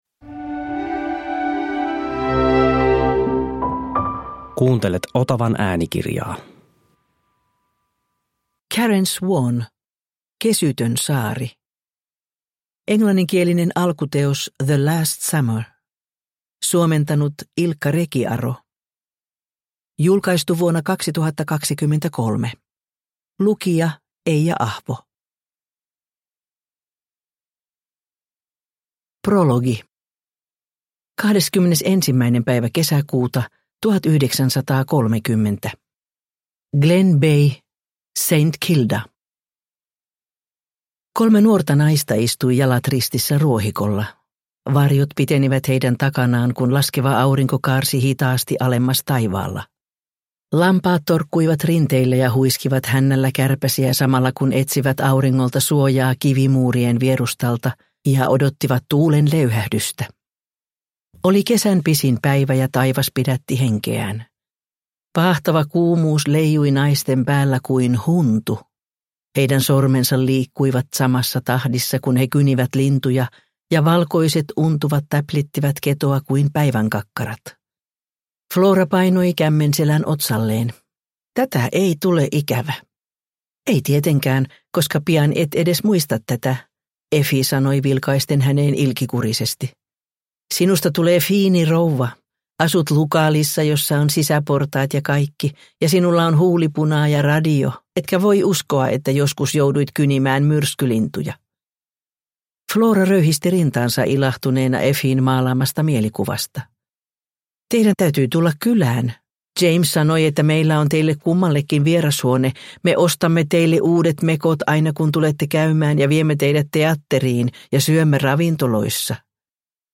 Kesytön saari – Ljudbok – Laddas ner